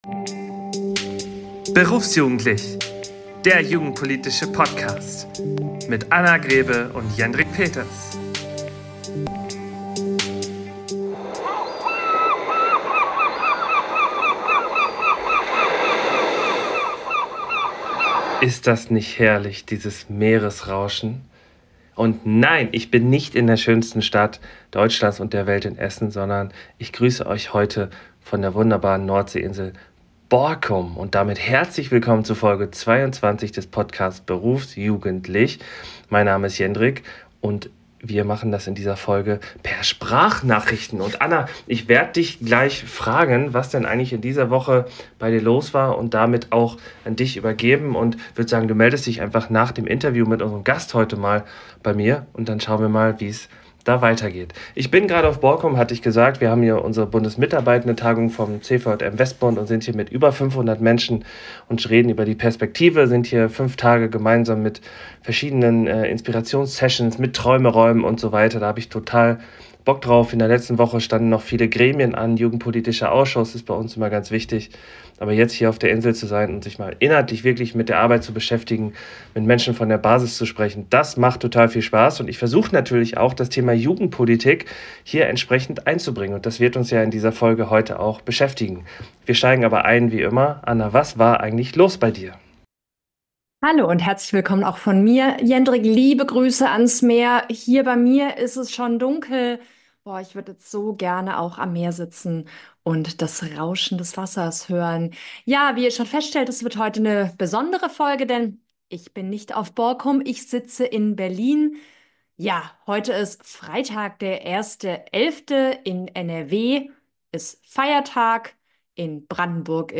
Beschreibung vor 1 Jahr Hört ihr die Möwen?